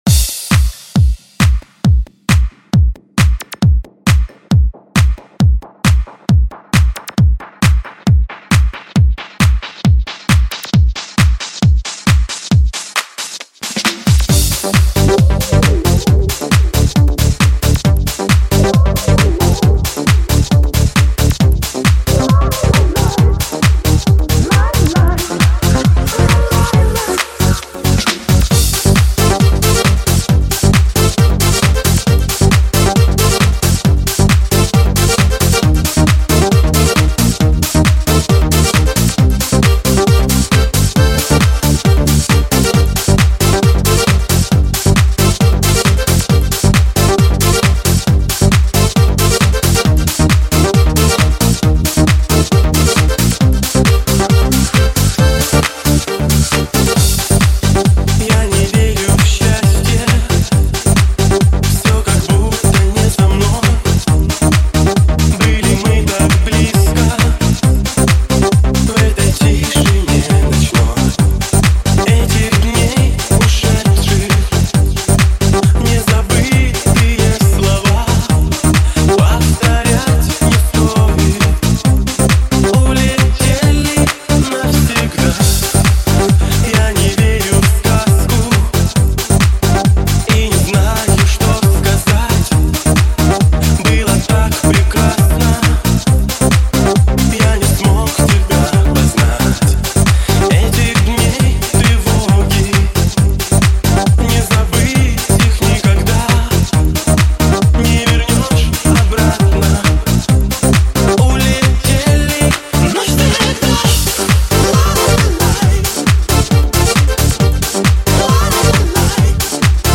_techno_90.mp3